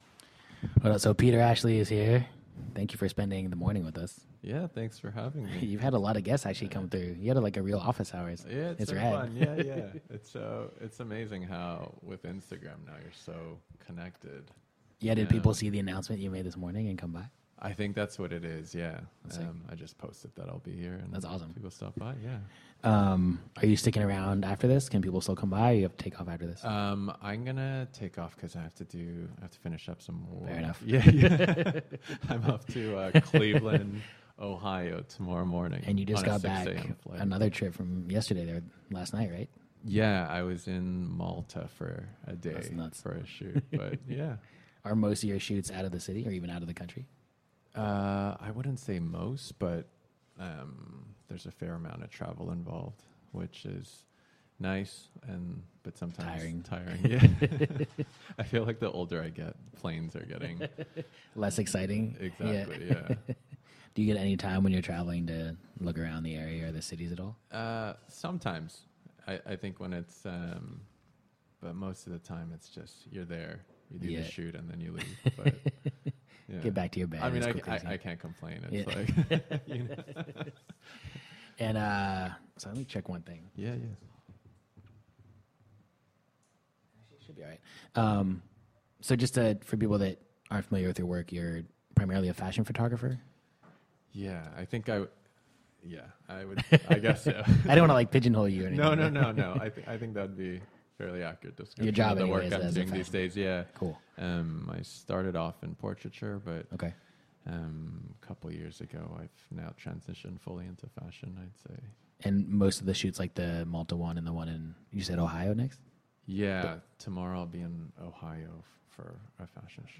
For season one, Office Hours , we took over a storefront in Chinatown and interviewed over 50 artists, designers, chefs, architects, entrepreneurs, and one politician, all to find out how they managed to make money doing what they love.
All of the interviews were recorded live.